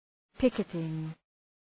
Προφορά
{‘pıkıtıŋ}